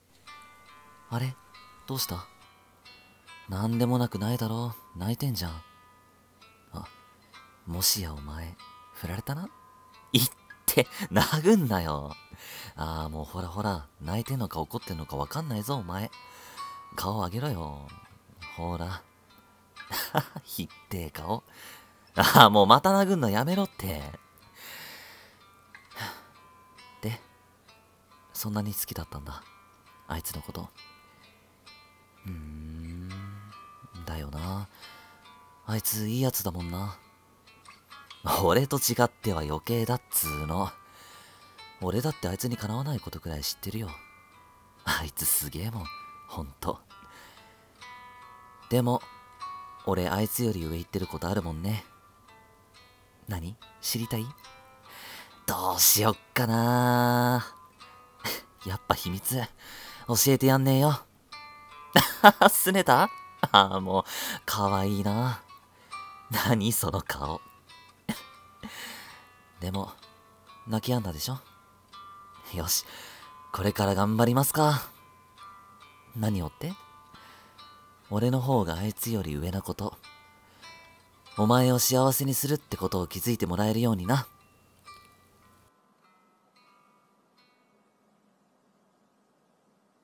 一人声劇 慰め【台本 男性用？】